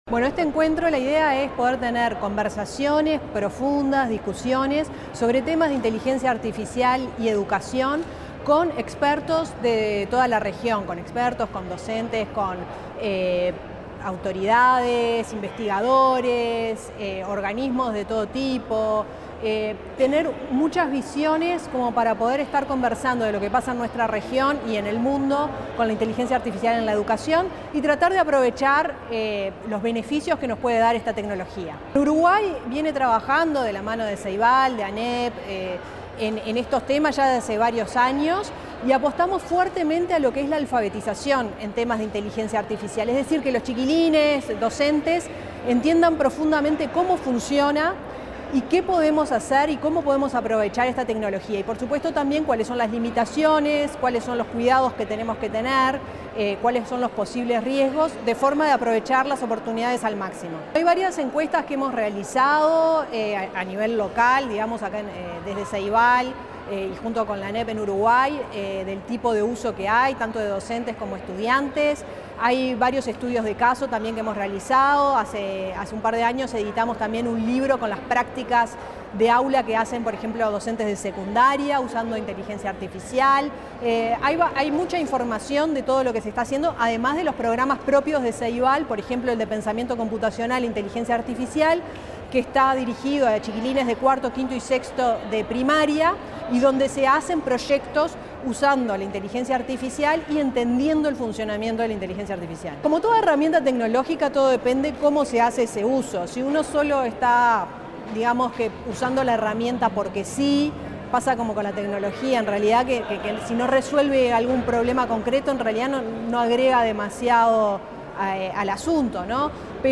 Declaraciones de la presidenta de Ceibal, Fiorella Haim